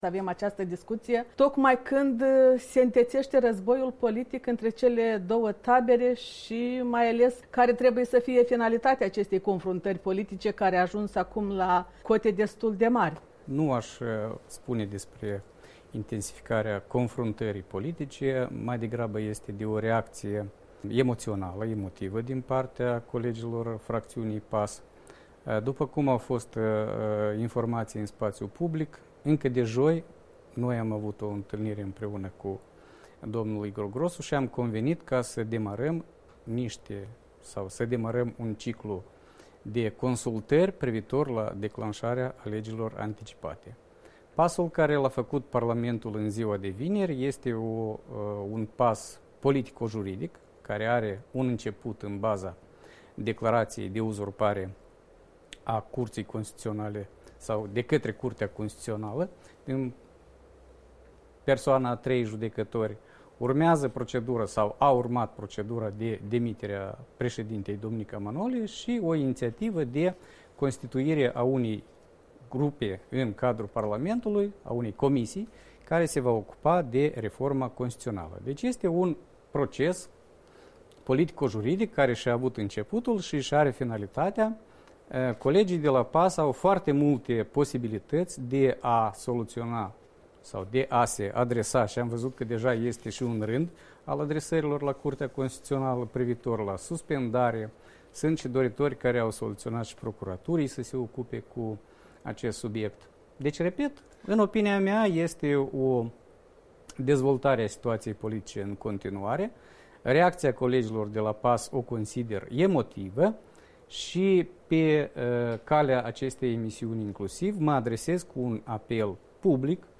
Interviu cu Corneliu Furculiță